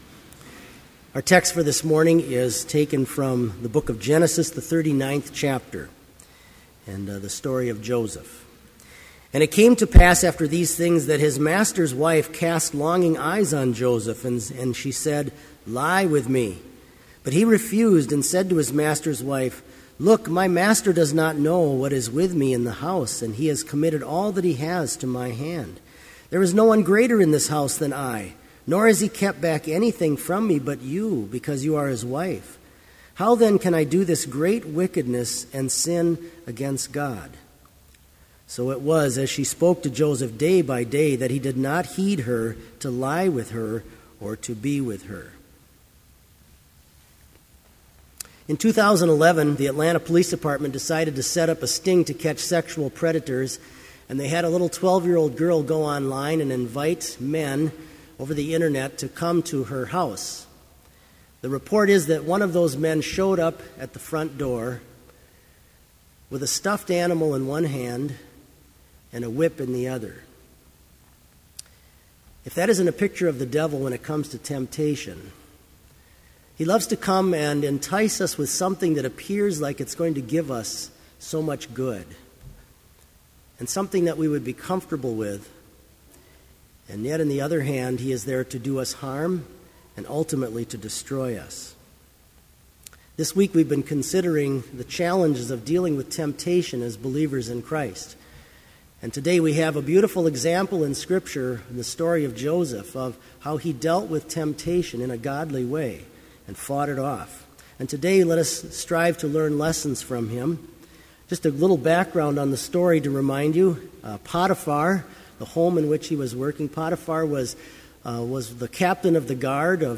Complete Service
• The Homily
• Postlude – Bethany Chapel Brass
This Chapel Service was held in Trinity Chapel at Bethany Lutheran College on Friday, February 22, 2013, at 10 a.m. Page and hymn numbers are from the Evangelical Lutheran Hymnary.